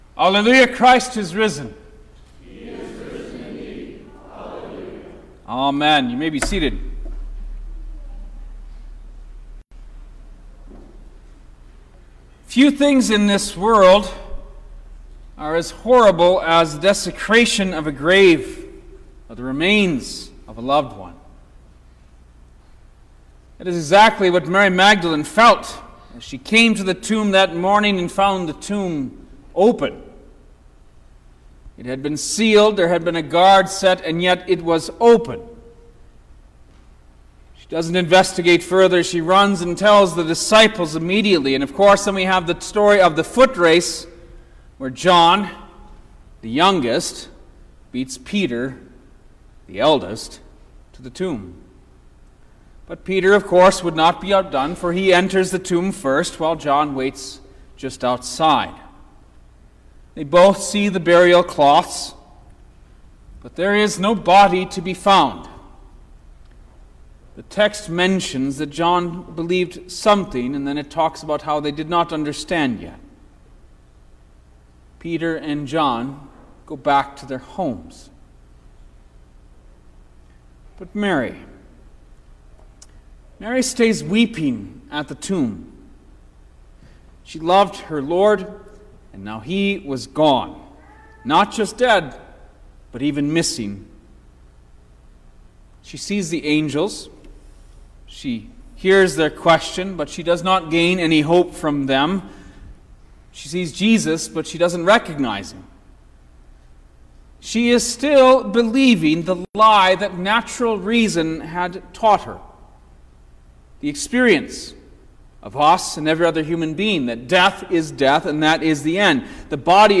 April-9_2023_Easter-Sunrise-Service_Sermon-Stereo.mp3